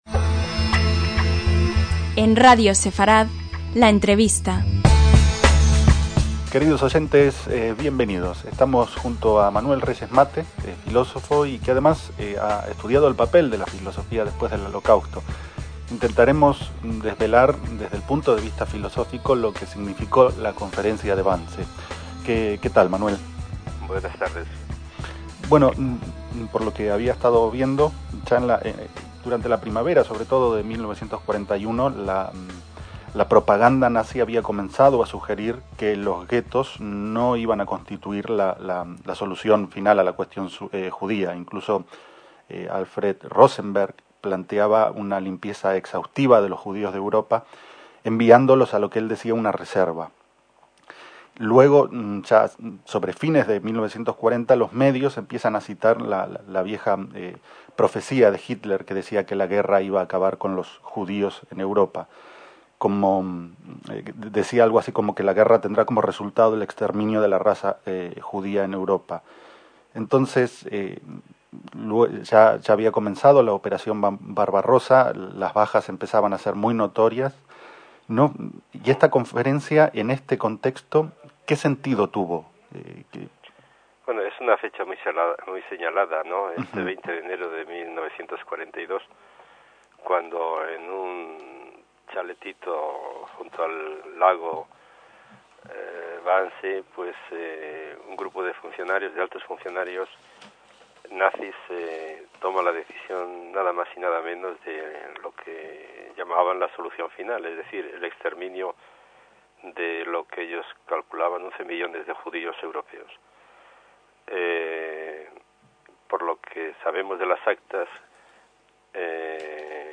UNA MIRADA A LA HISTORIA - Hace 10 años, cuando se cumplían 70 años de la Conferencia de Wannsee que reunió a los principales jerarcas nazis para dar forma a lo que llamaron la "Solución Final" (el exterminio de los judíos de Europa), entrevistamos a Manuel Reyes Mate, especialista en el papel de la filosofía después del Holocausto y Auschwitz.